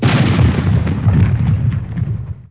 Explode.mp3